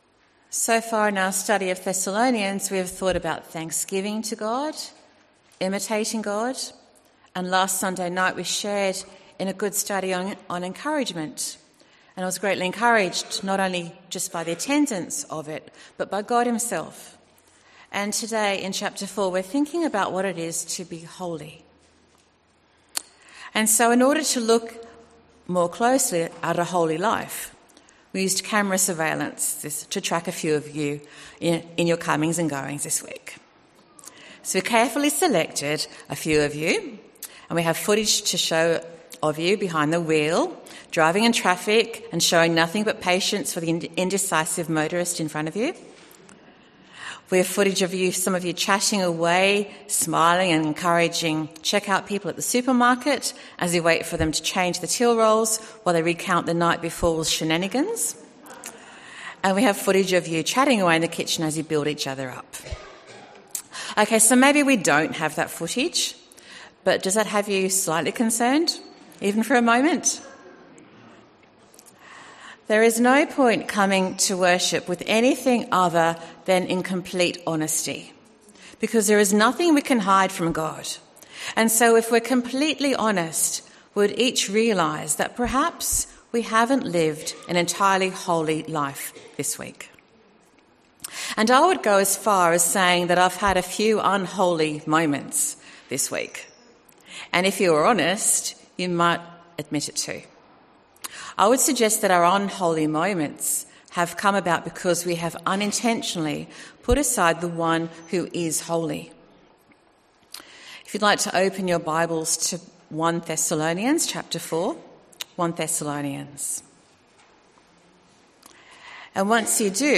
Sermon from the 10AM meeting at Newcastle Worship & Community Centre of The Salvation Army. The sermon is on the theme of 'Holy' and is taken from 1 Thessalonians 4:1-18.